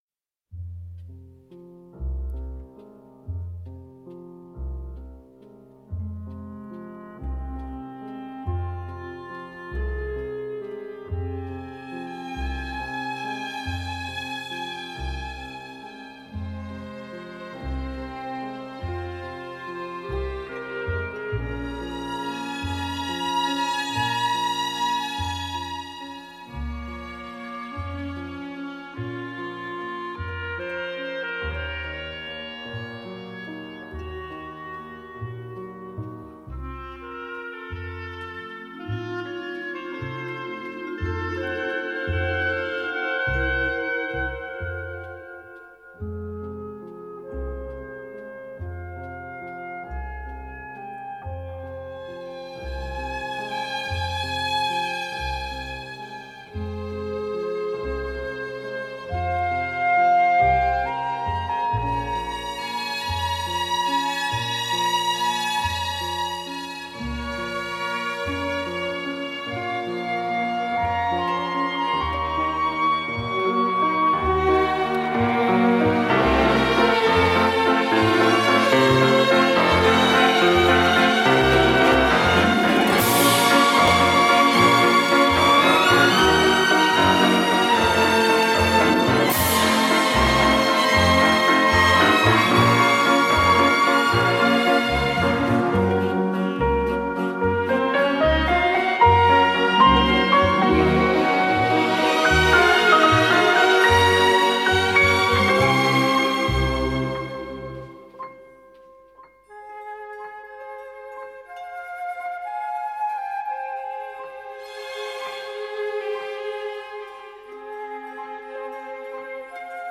Музыка к кинофильмам